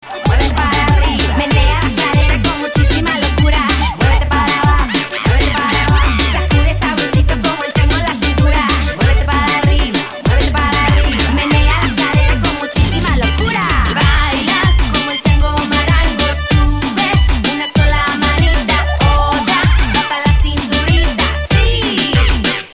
children's music